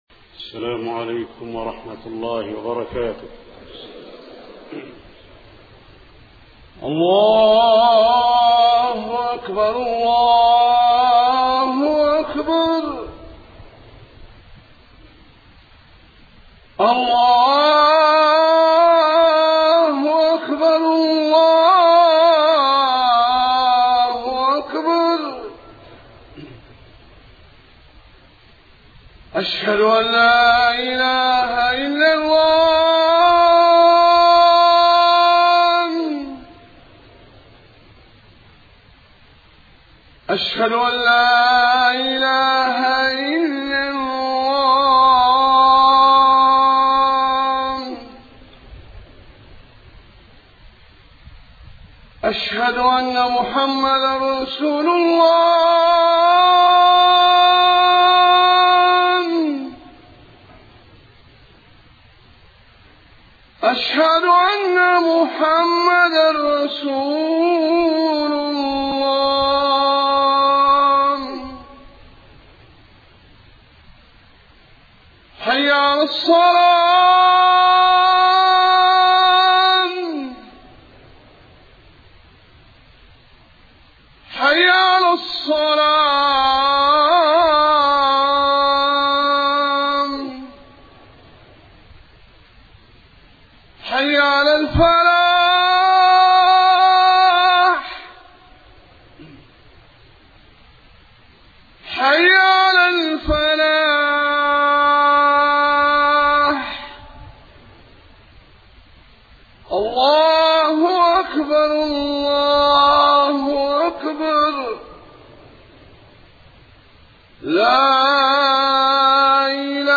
خطبة الجمعة 28 صفر 1431هـ > خطب الحرم النبوي عام 1431 🕌 > خطب الحرم النبوي 🕌 > المزيد - تلاوات الحرمين